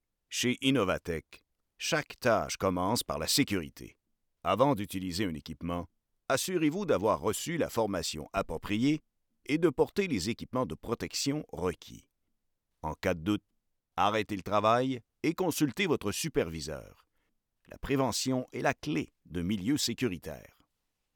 Diep, Vertrouwd, Volwassen
E-learning